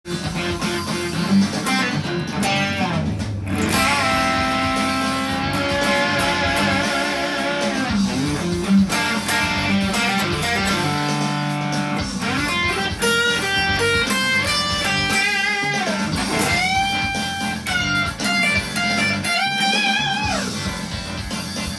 マイナペンタトニックスケール
アドリブで弾いてみました。
マイナーペンタトニックスケールはブルースやロックの王道スケールになるので